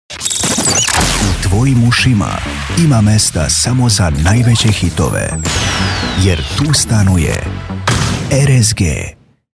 Jinglovi – Voice za jingle. Snimanje i obrada